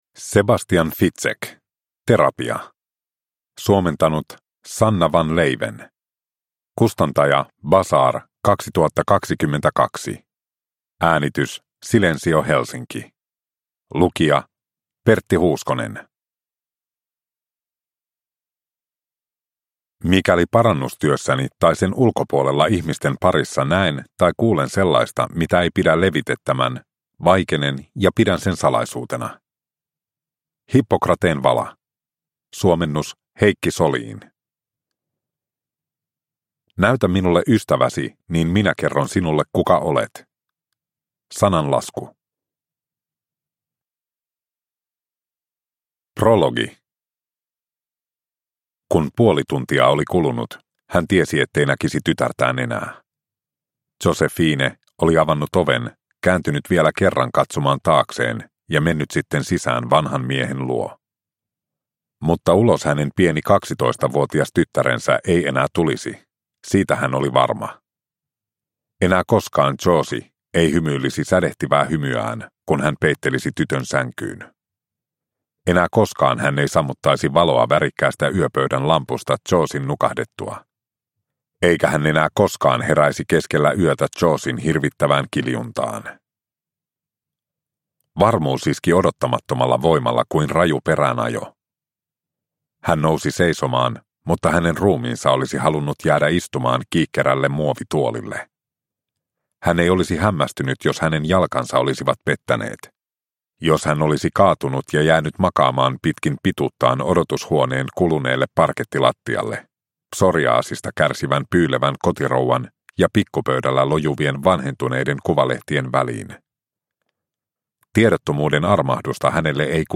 Terapia – Ljudbok – Laddas ner